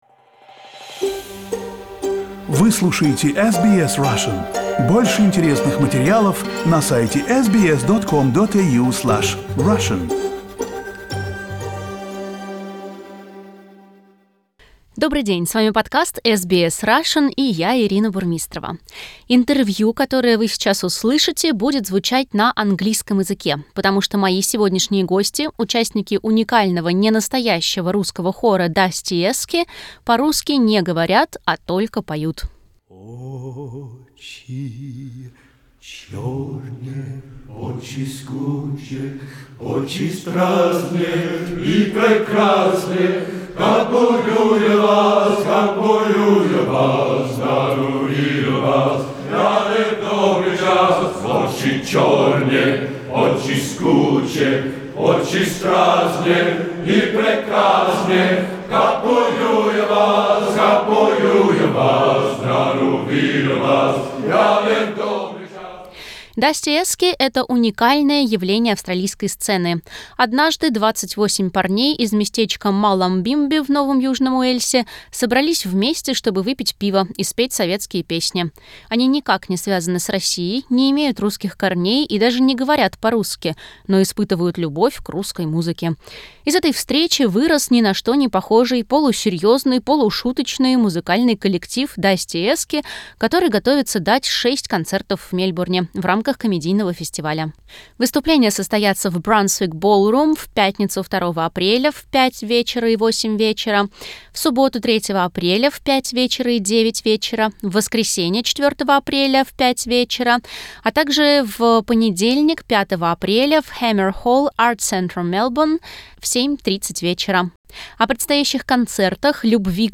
The members of the Russian choir Dustyesky talk about the upcoming concerts, their favorite songs, the Russian audience and their trip to Moscow. Interview in English.